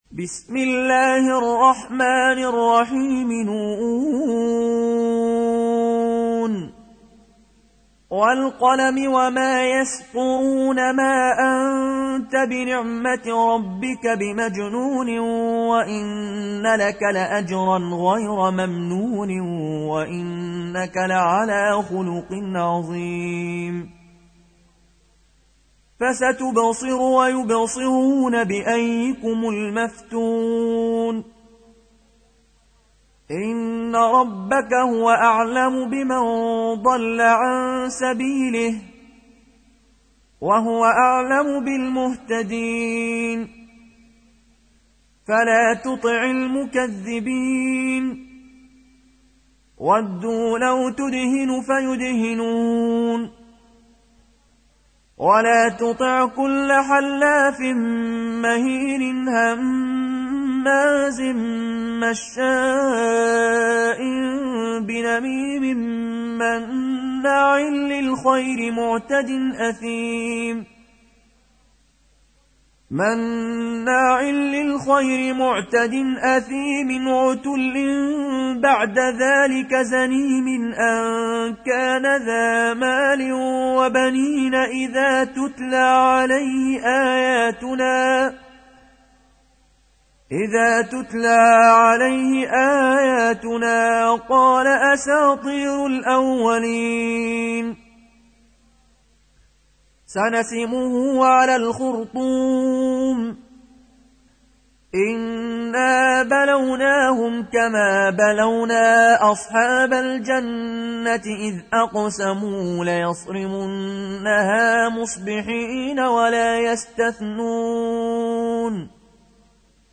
Tarteel Recitation
Surah Sequence تتابع السورة Download Surah حمّل السورة Reciting Murattalah Audio for 68. Surah Al-Qalam سورة القلم N.B *Surah Includes Al-Basmalah Reciters Sequents تتابع التلاوات Reciters Repeats تكرار التلاوات